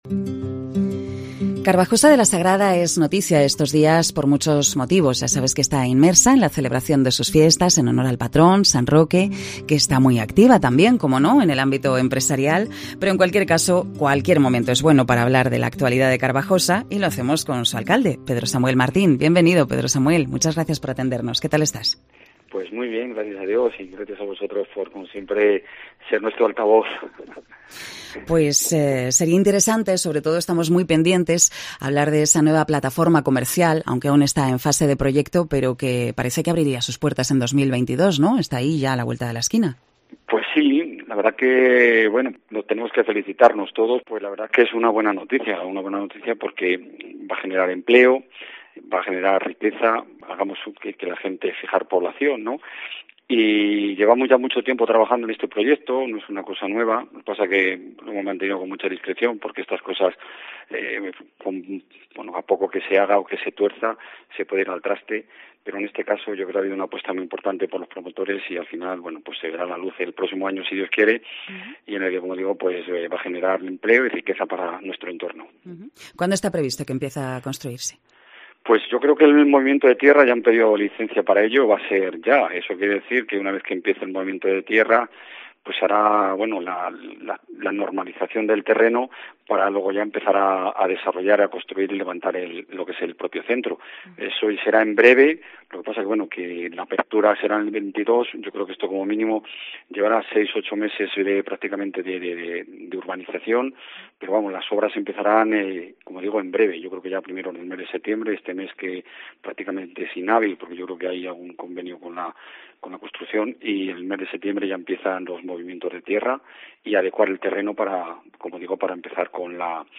Cope Salamanca entrevista a Pedro Samuel Martín, alcalde de Carbajosa de la Sagrada